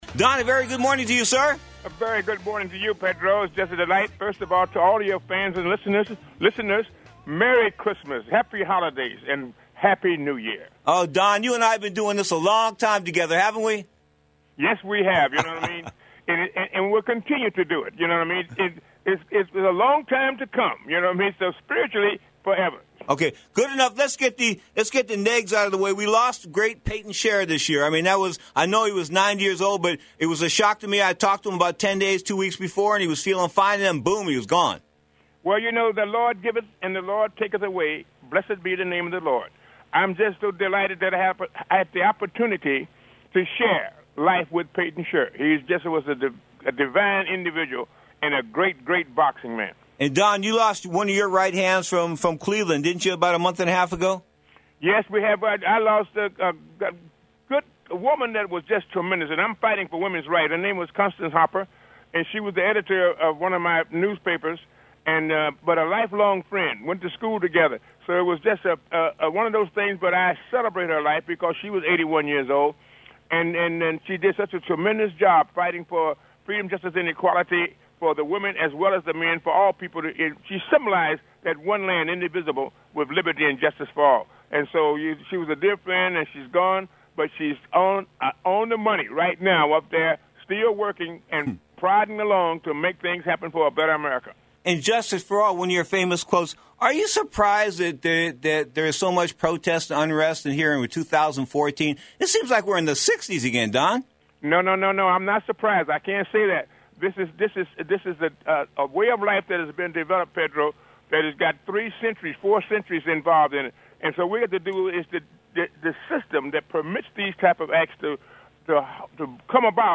If you Don “Unplugged” King know anything about Don, he doesn’t disappoint in an interview!